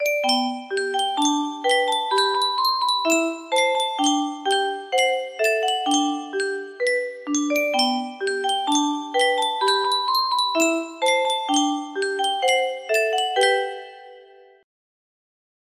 Yunsheng Music Box - Grandfather's Clock 1034 music box melody
Full range 60